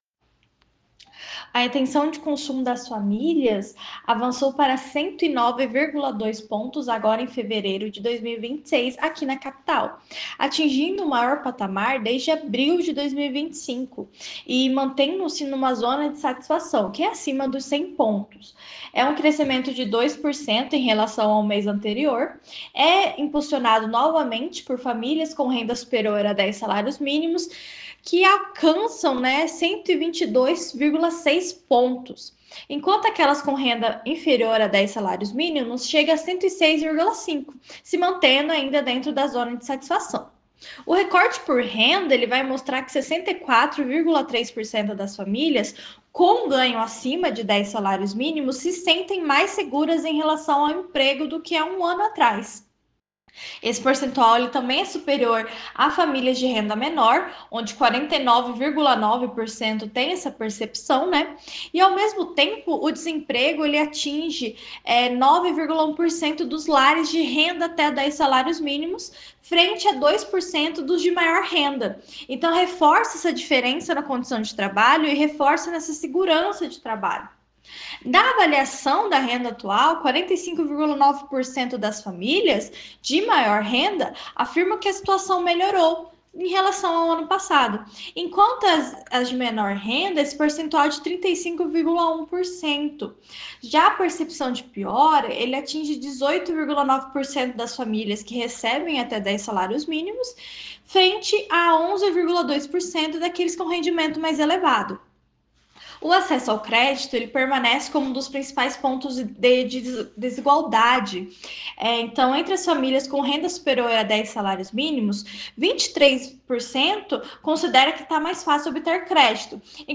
Em entrevista à FM Educativa MS